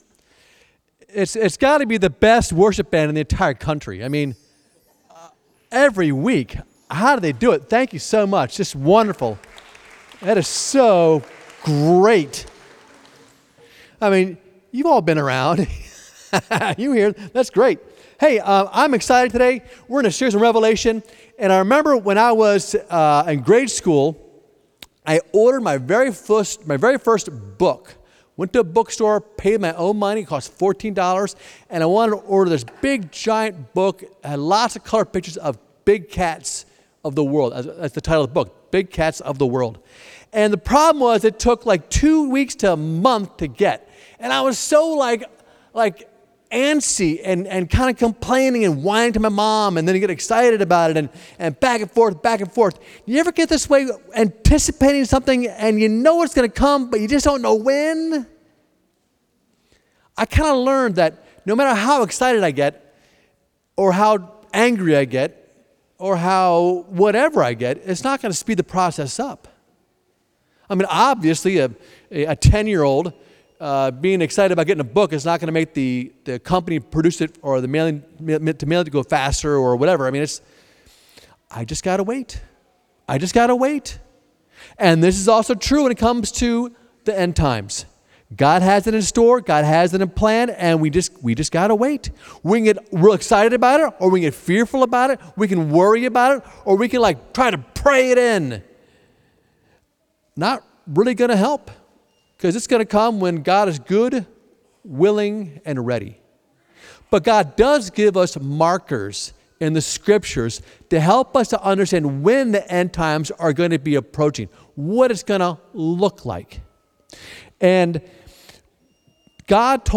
Oct-6-2024-SIF-Sermon.mp3